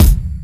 MB Kick (16).wav